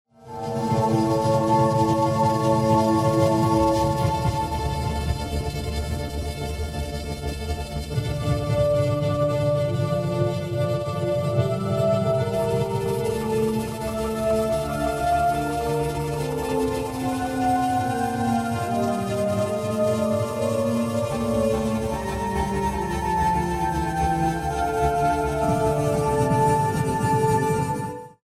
Voice:  Bengali child singer